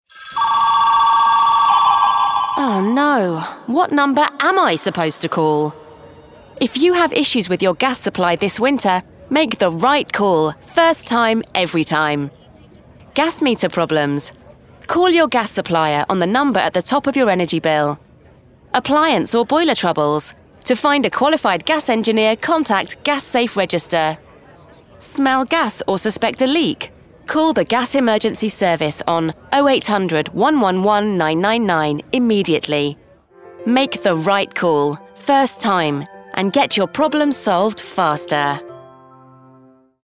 Radio clip - 40 second clip for a radio advertisement
Cadent-Gas-Make-The-Right-Call-Radio-Clip.mp3